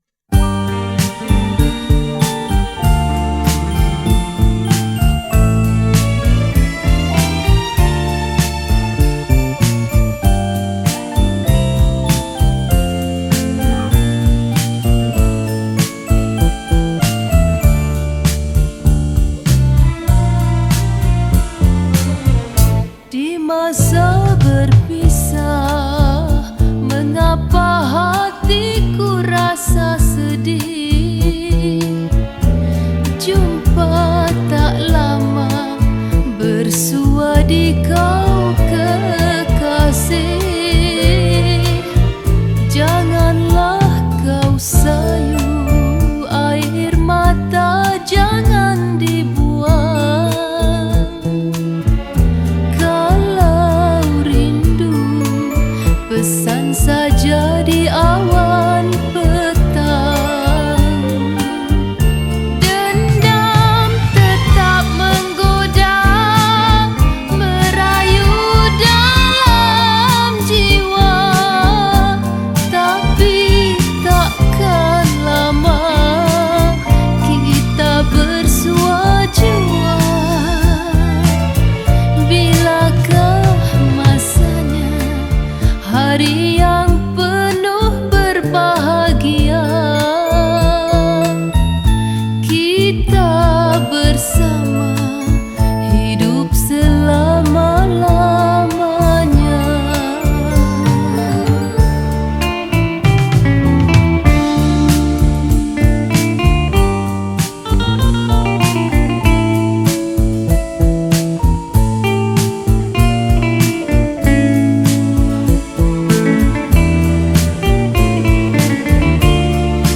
Malay Songs
Skor Angklung